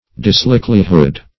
Search Result for " dislikelihood" : The Collaborative International Dictionary of English v.0.48: dislikelihood \dis*like"li*hood\, n. The want of likelihood; improbability.
dislikelihood.mp3